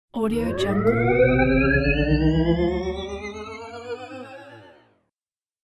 دانلود افکت صوتی چرخیدن و پرواز سفینه فضایی کوچک